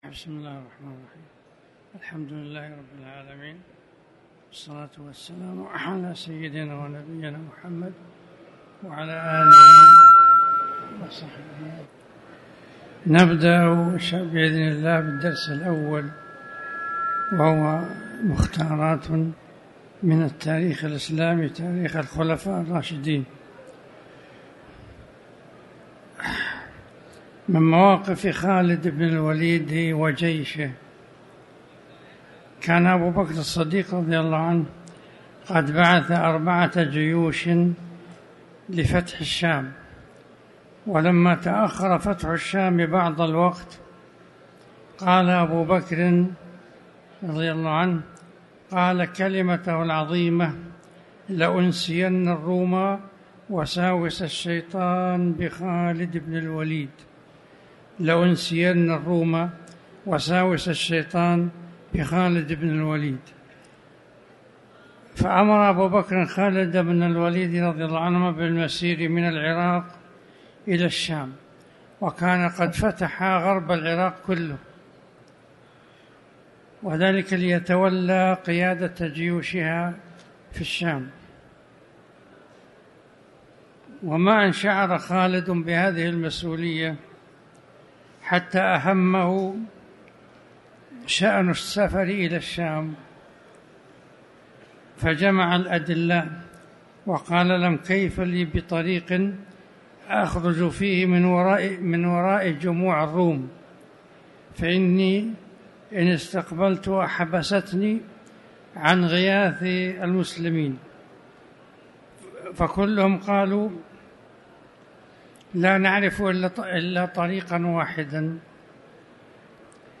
تاريخ النشر ١١ ذو القعدة ١٤٤٠ هـ المكان: المسجد الحرام الشيخ